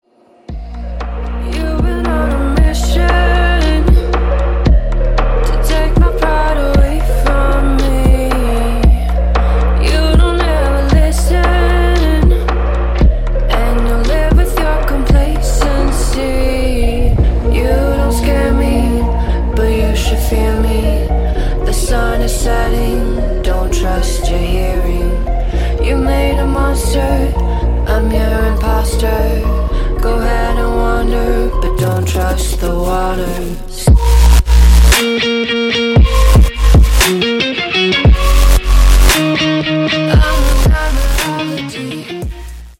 • Качество: 128, Stereo
атмосферные
Electronic
Trap
чувственные
красивый женский голос
future bass